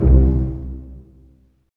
Index of /90_sSampleCDs/Roland LCDP13 String Sections/STR_Vcs Marc&Piz/STR_Vcs Pz.2 amb
STR PIZZ.01R.wav